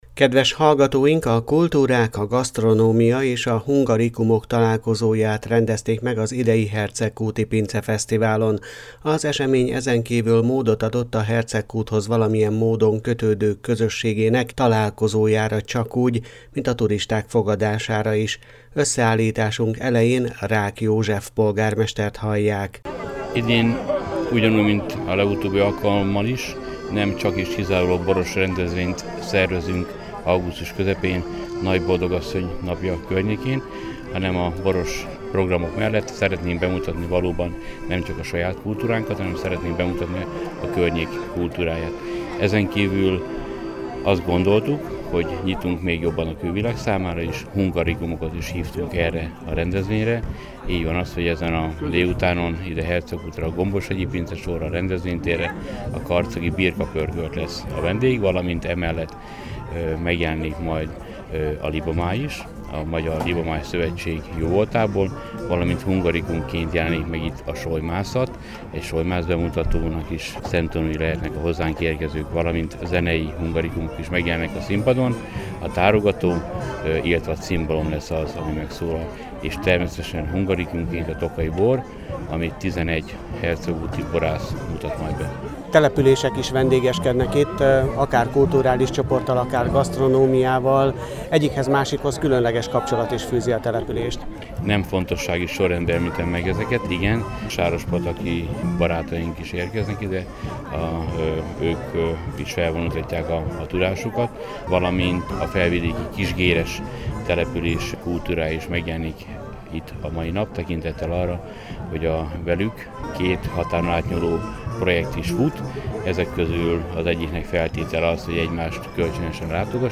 Összeállításunk elején Rák József polgármestert hallják.